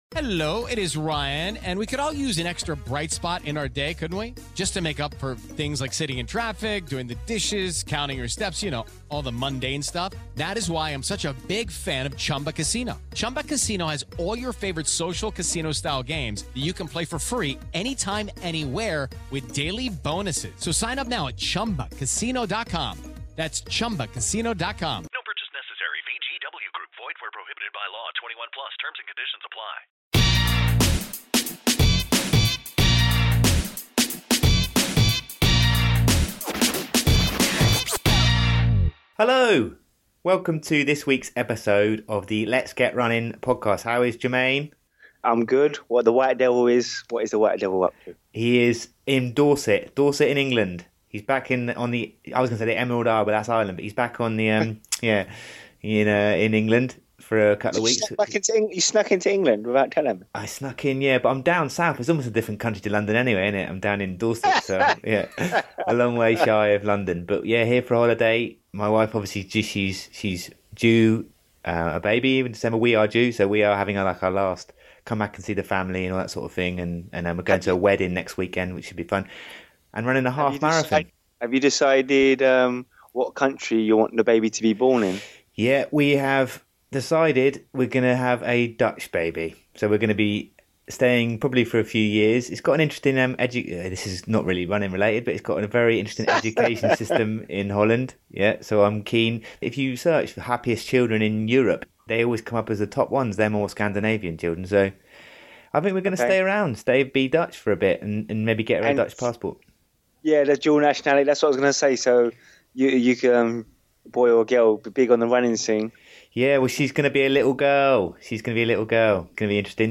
On this week's show we talk to top British athlete, Andy Vernon.